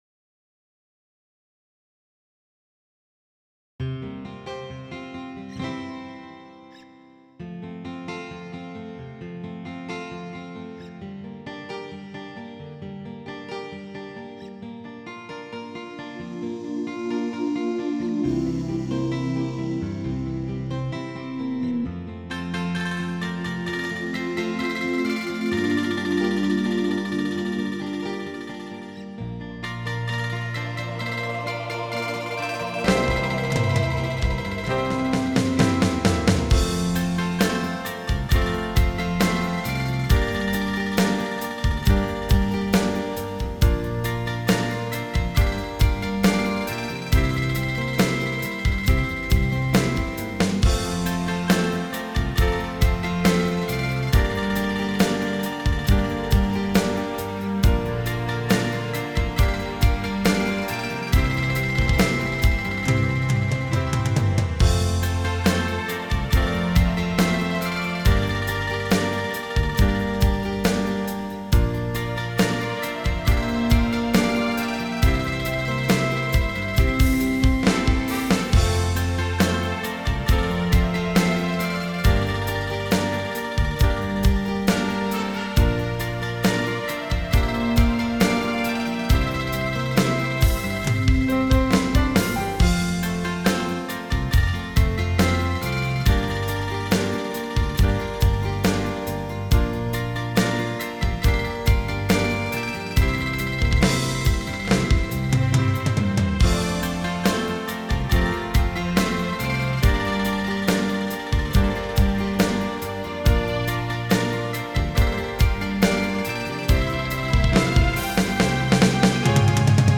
минусовка версия 120641